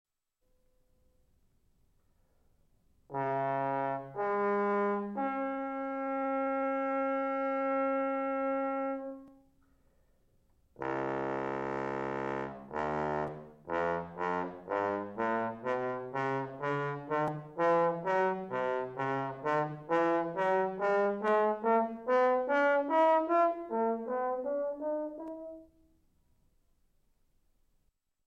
Audition Tape (April 1984)
Douglas Yeo, bass trombone.